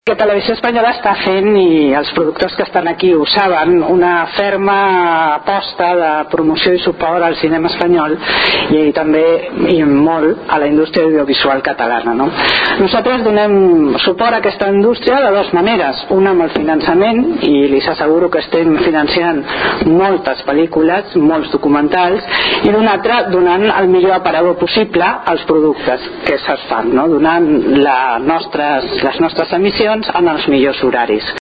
Intervencions durant la roda de premsa
Tall de veu